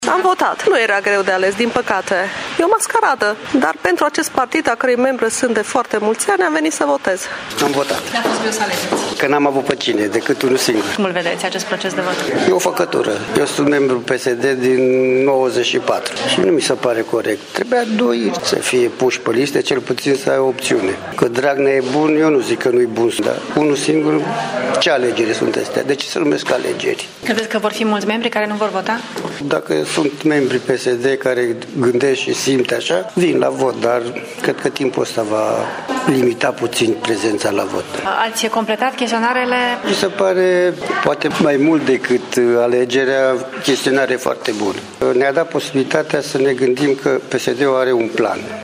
Votanții spun că acest proces de vot este o ”făcătură” :